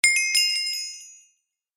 На этой странице собраны звуки крипера из Minecraft — от характерного шипения до взрыва.
Minecraft — новый уровень вверх